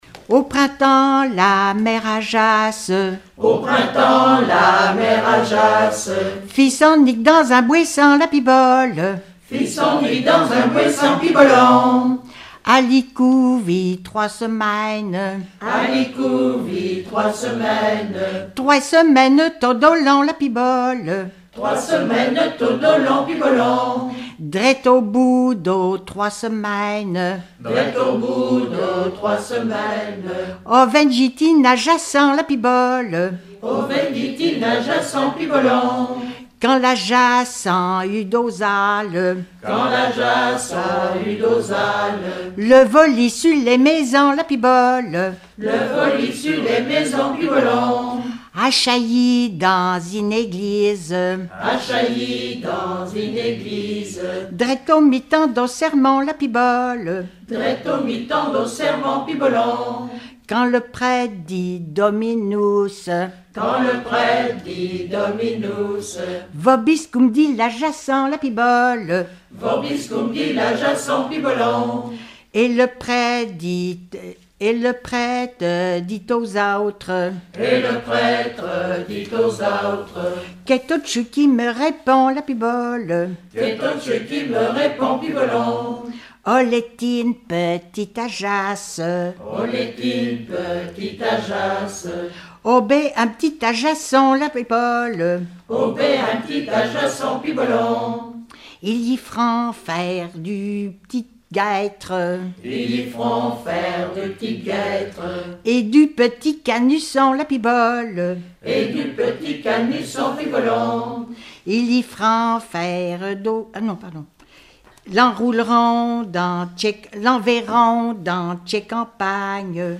Collectif-veillée (2ème prise de son)
Pièce musicale inédite